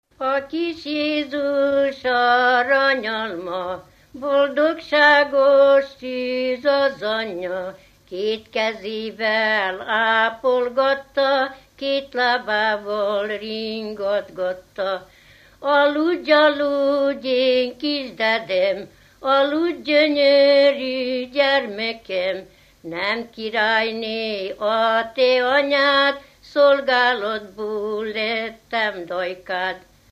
Alföld - Bács-Bodrog vm. - Doroszló
ének
Műfaj: Karácsonyi ének
Stílus: 7. Régies kisambitusú dallamok
Szótagszám: 8.8.8.8
Kadencia: 1 (1) 5 1